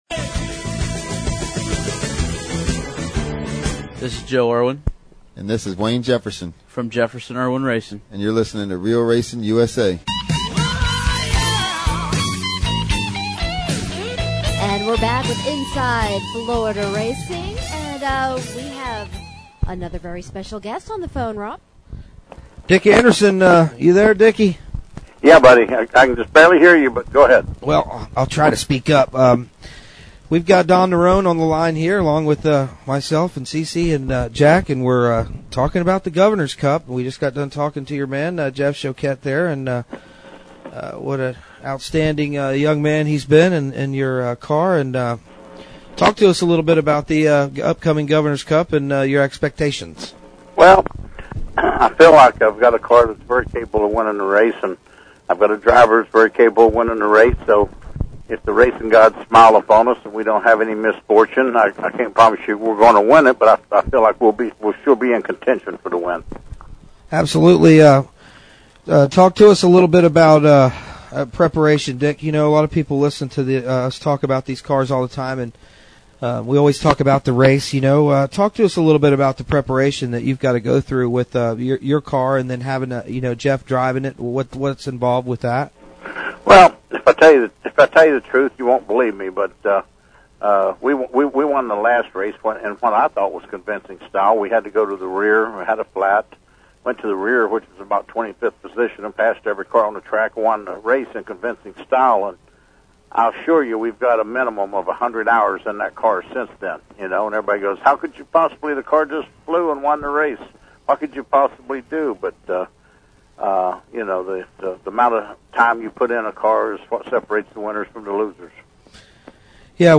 FULL INTERVIEW LINK